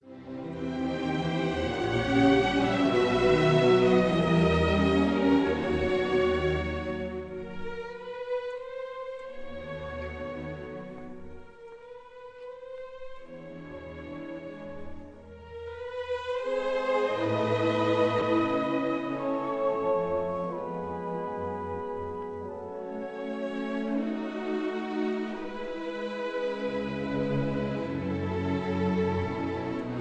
conductor
Recorded on 17 September 1951